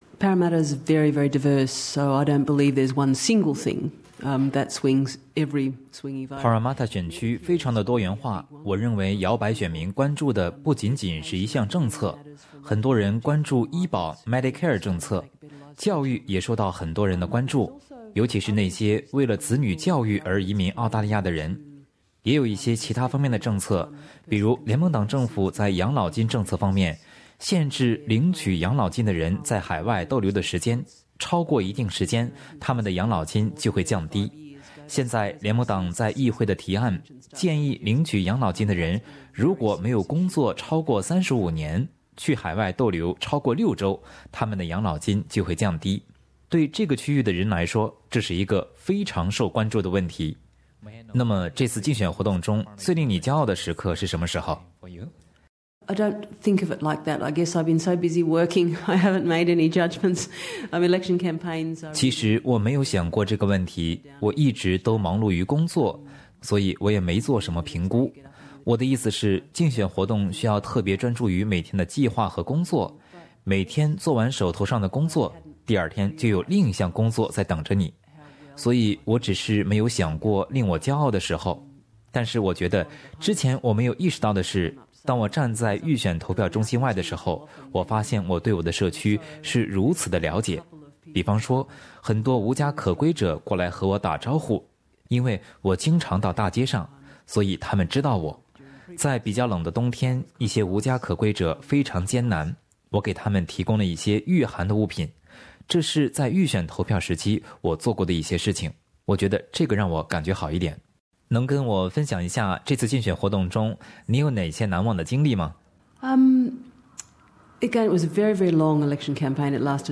她在大選結束的噹天接受了本台記者的寀訪，她認為作為邊緣選區的Parramatta，非常多元化，競爭很激烈。那麼，在她眼中，選民們，尤其搖襬選民們最關注哪些政策呢？